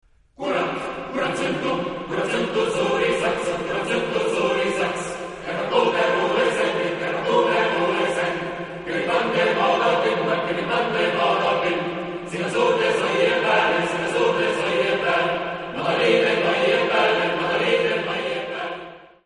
Genre-Style-Form: Partsong ; Folk music ; Secular
Type of Choir: SATB  (4 mixed voices )
Tonality: F major